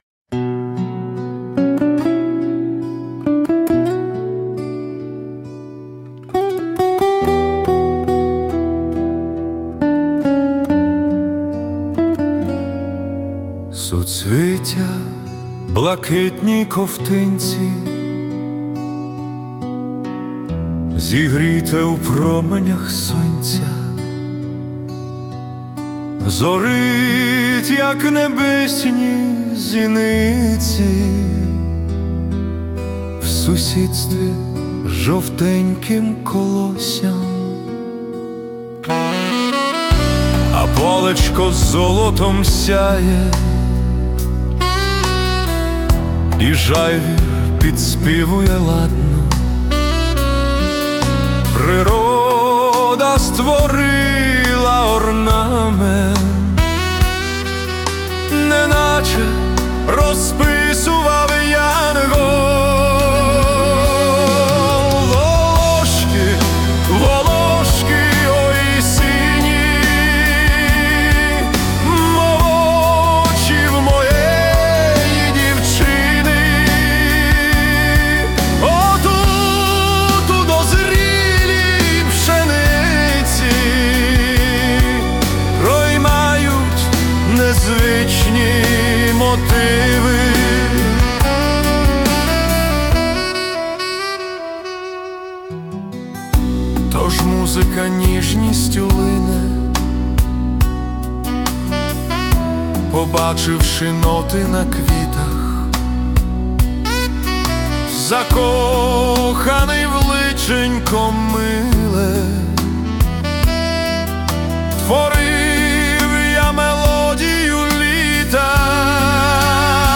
Музична композиція створена за допомогою SUNO AI